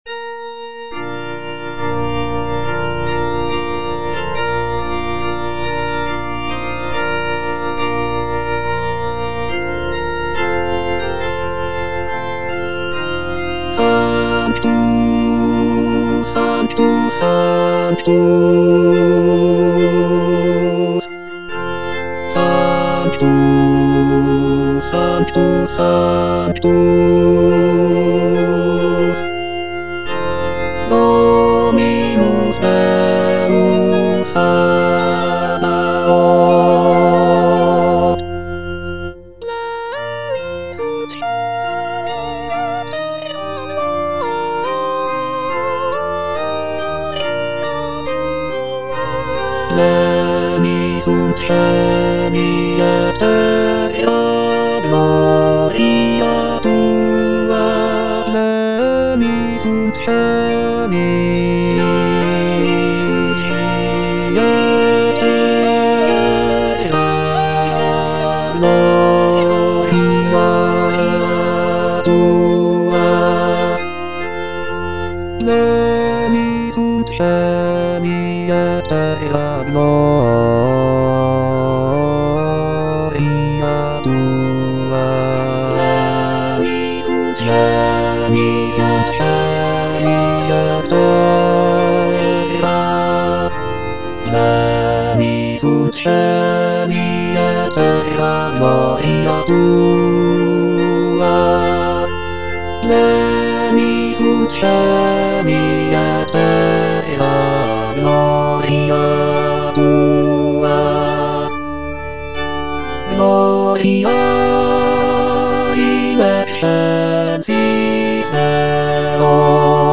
Bass (Emphasised voice and other voices) Ads stop
is a sacred choral work rooted in his Christian faith.